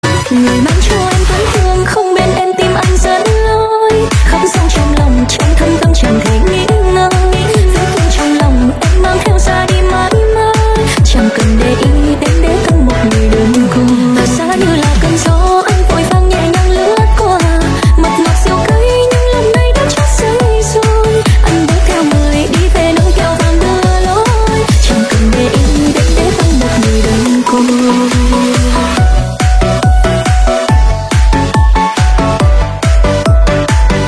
Nhạc Remix.